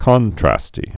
(kŏntrăstē)